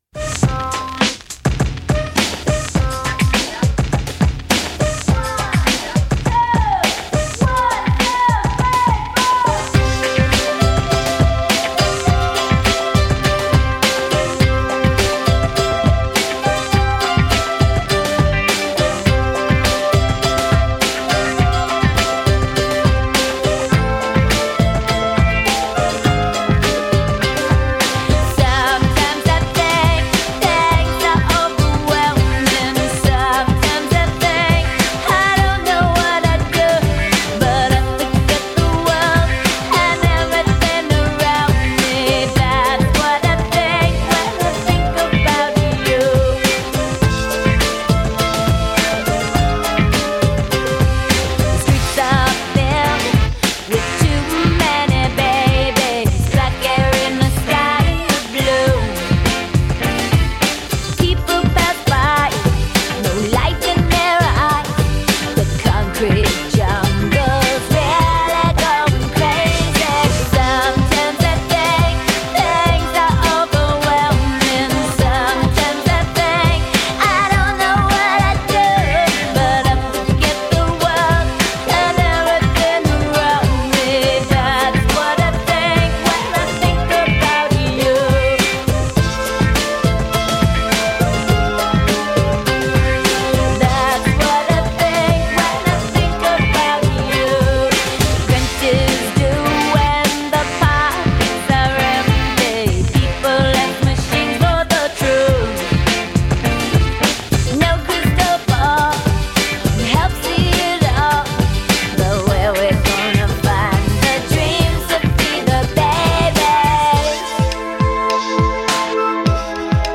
【唱片风格】流行